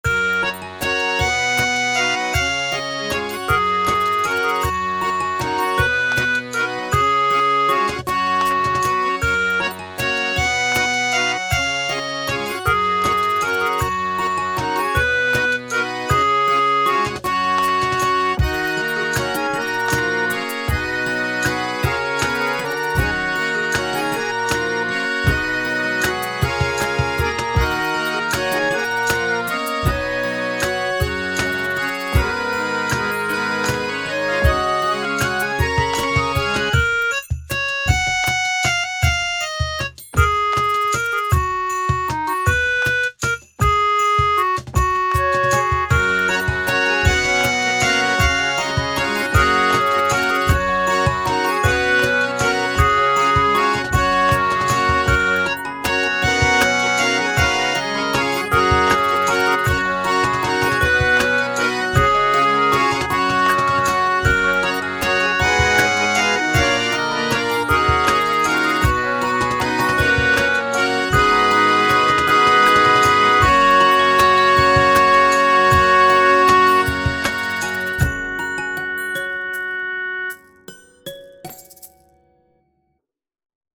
かわいい ファンタジー